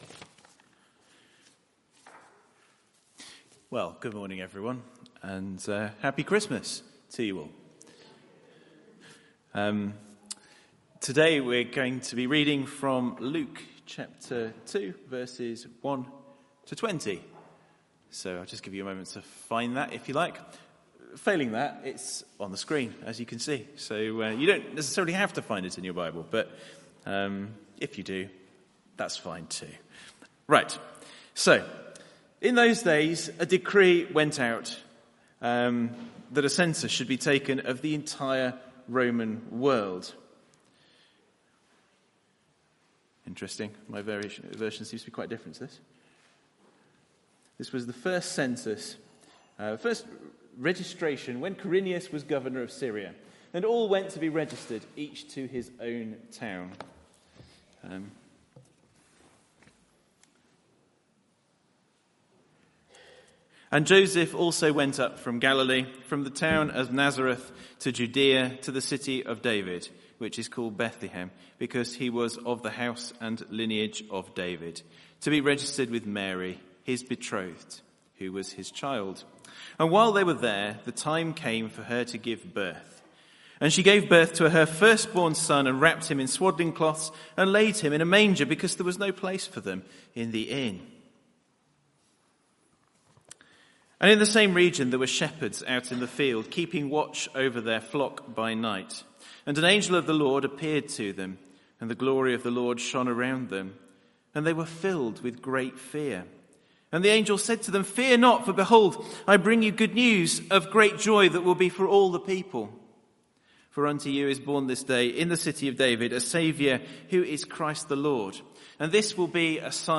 Christmas Day Service 2021, Christmas Day Sermon 2021